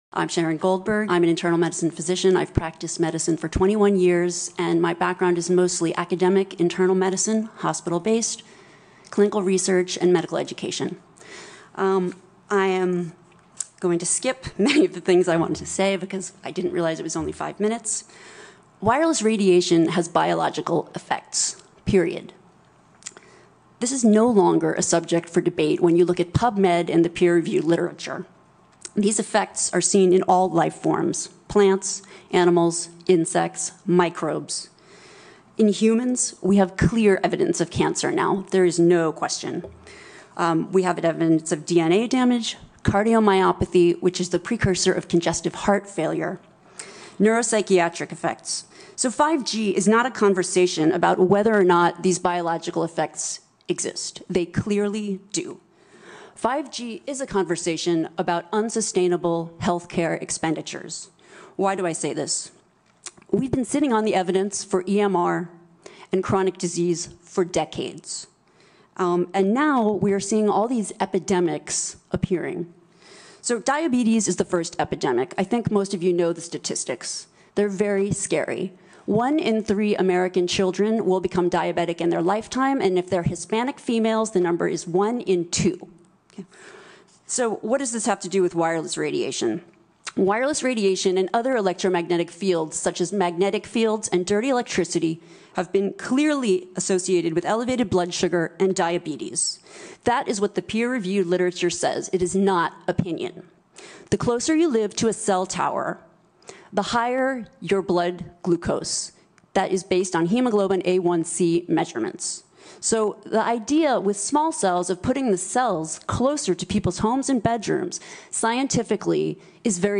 בעדות לסנאט במישיגן